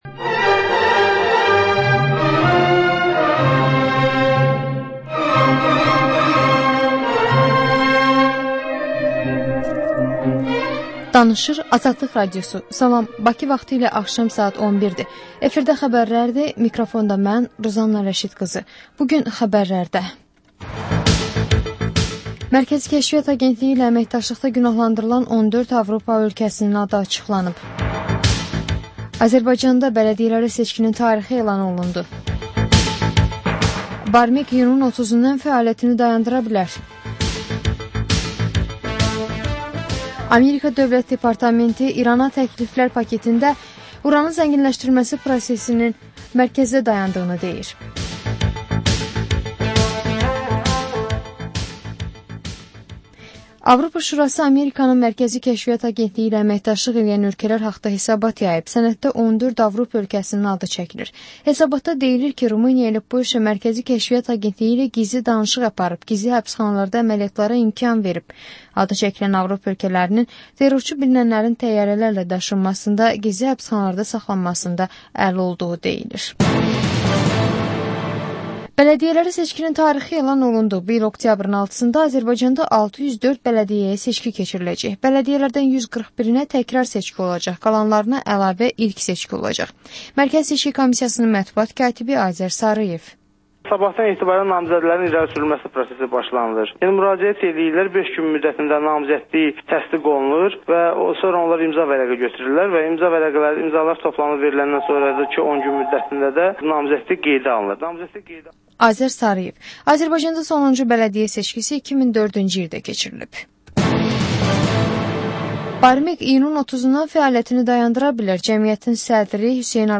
Xəbər, reportaj, müsahibə.